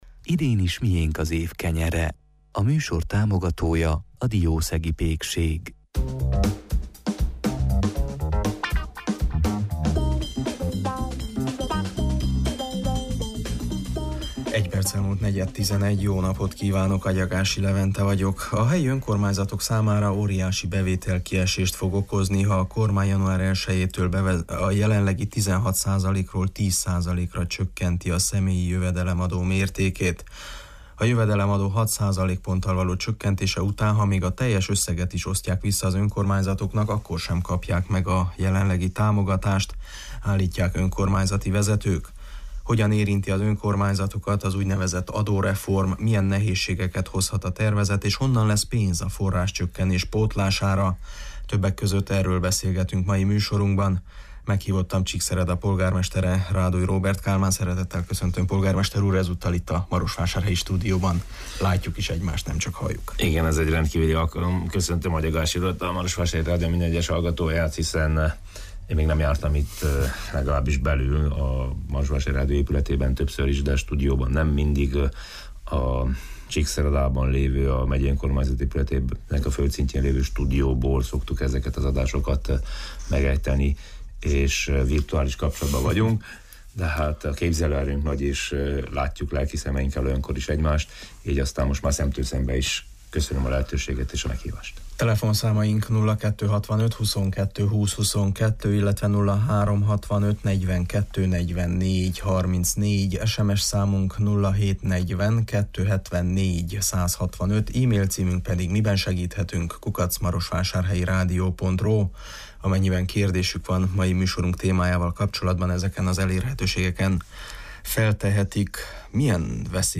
A kormány adómódosító intézkedéseinek jó és rossz hatásairól beszélt szerdai műsorunkban Csíkszereda polgármestere, Ráduly Róbert Kálmán: